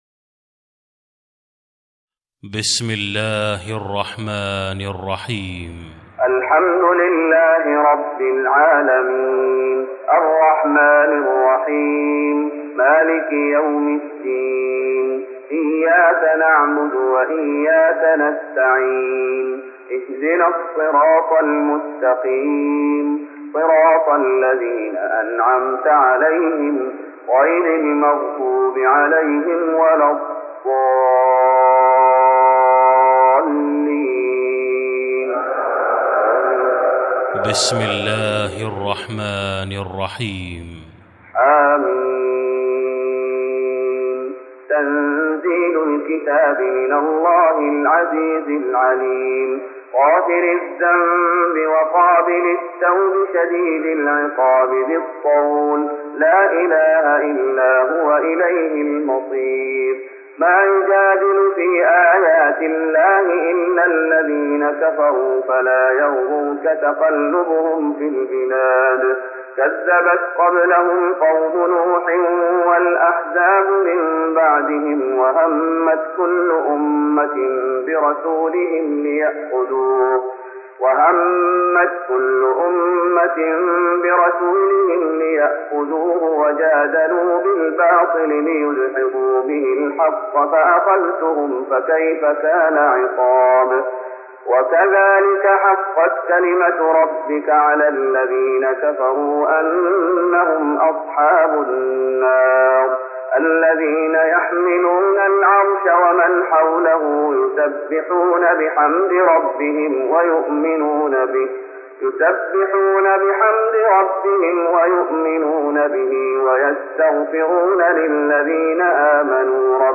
تراويح رمضان 1414هـ من سورة غافر Taraweeh Ramadan 1414H from Surah Ghaafir